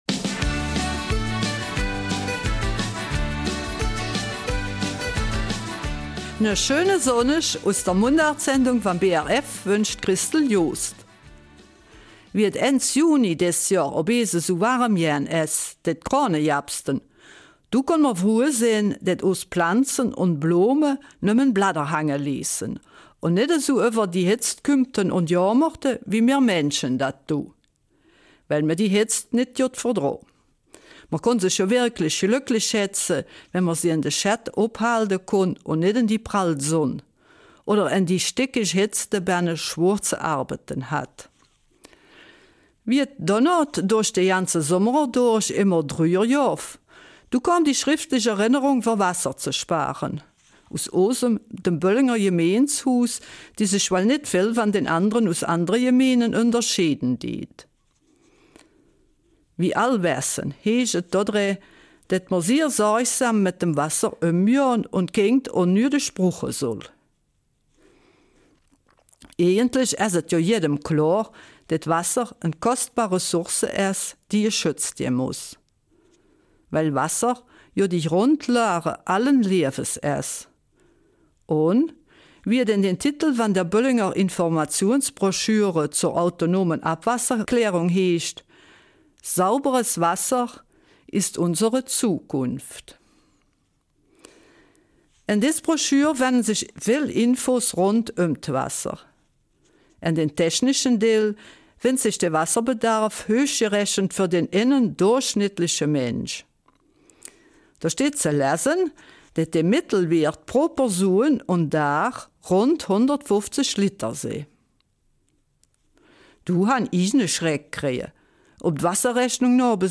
Eifeler Mundart: Rund um das Thema ''Wasser''